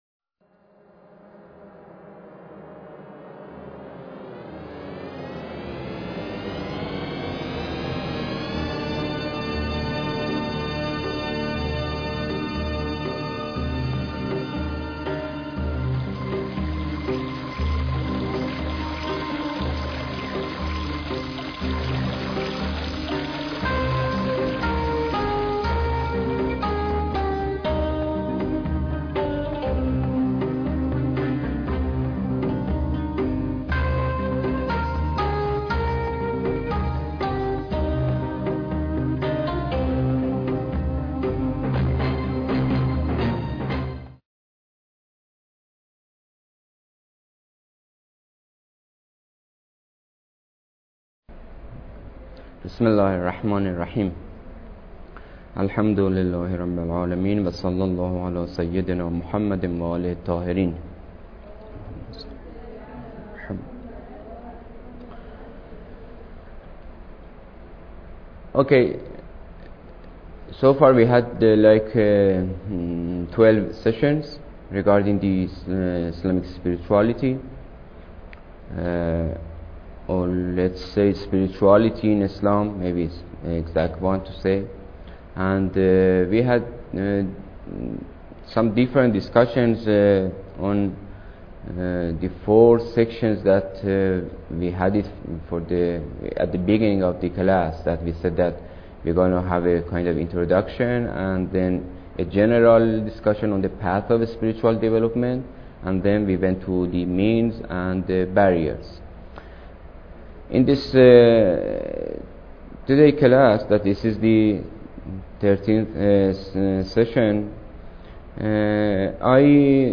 Lecture_13